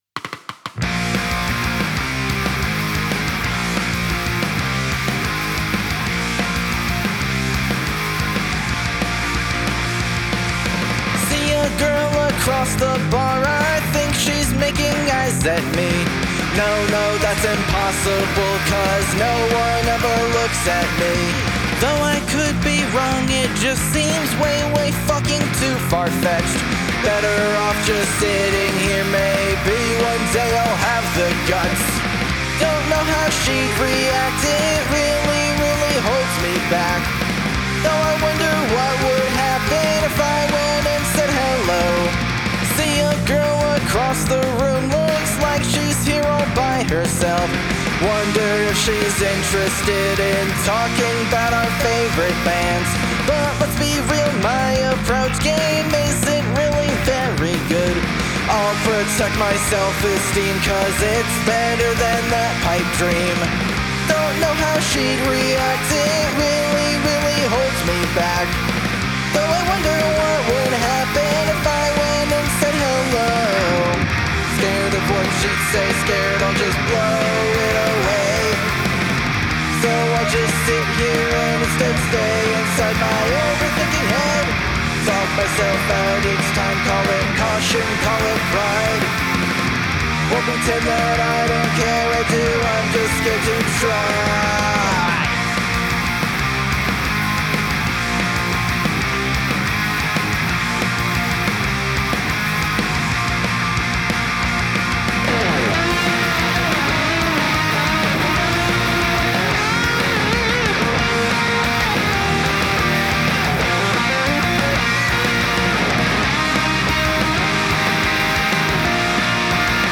and Vocals
Guitar and Bass